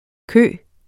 kø substantiv, fælleskøn Bøjning -en, -er, -erne Udtale [ ˈkøˀ ] Oprindelse fra fransk queue 'hale' Betydninger 1.